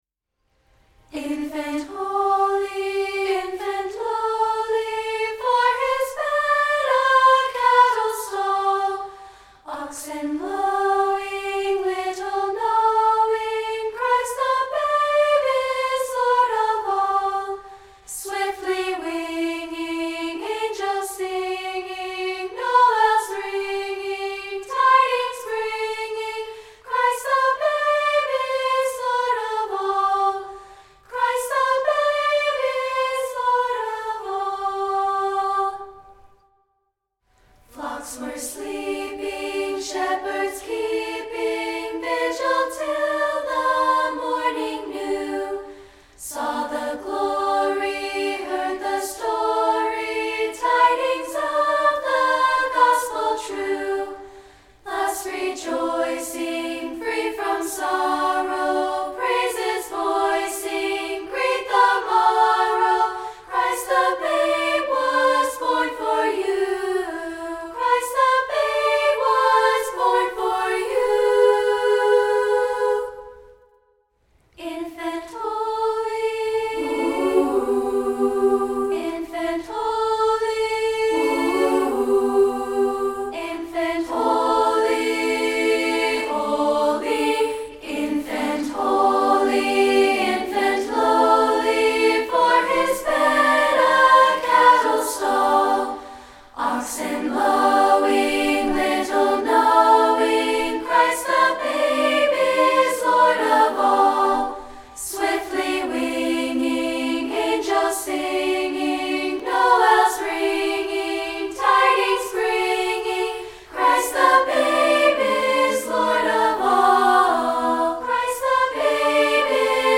Christmas song
including an a cappella recording.